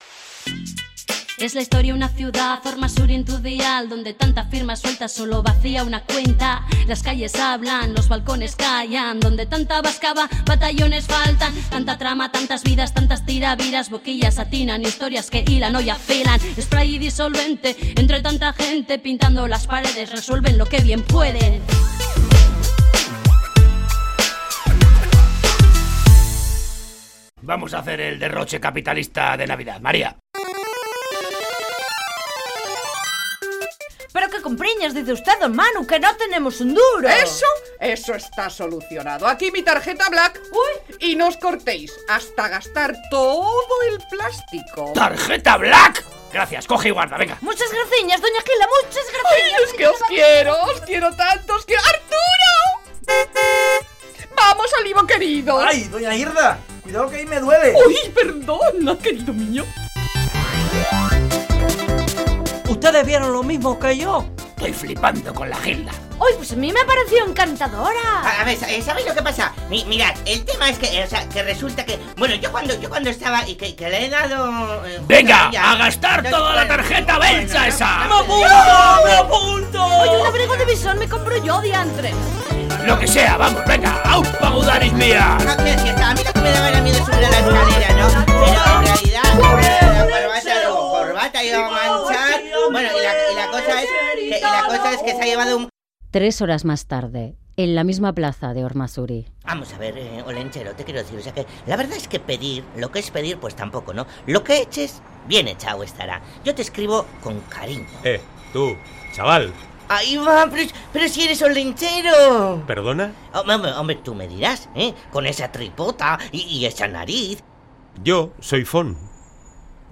Entrega 42 de la Radio-Ficción “Spray & Disolvente” fin 1ªtemporada